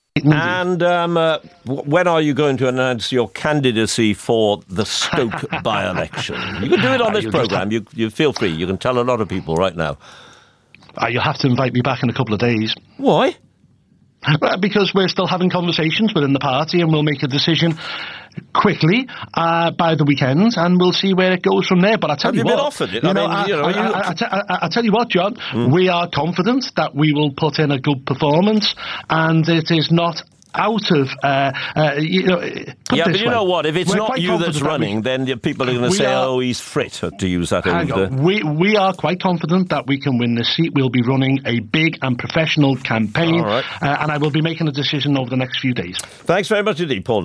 Not convined? Have a listen to this clip broadcast on BBC Radio 4’s Today Programme yesterday 17th January: